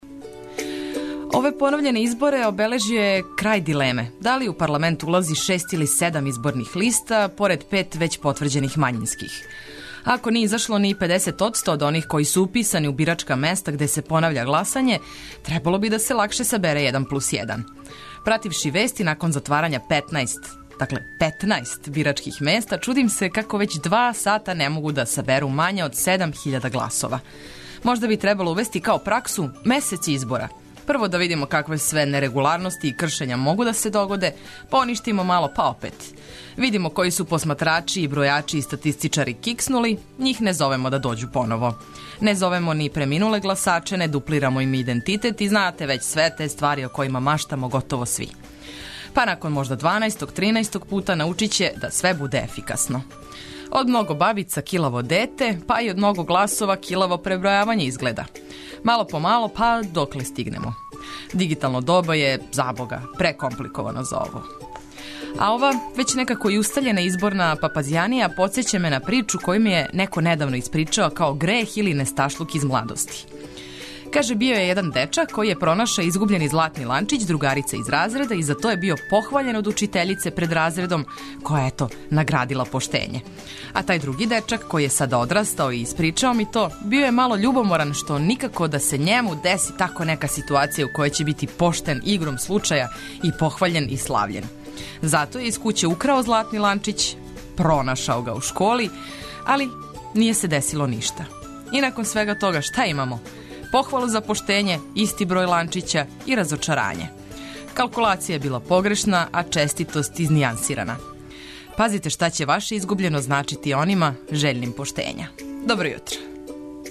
Разбуђујемо се у ведрини омиљене музике, и поткивањем свим битним информацијама, али ни смех никако нећемо заборавити!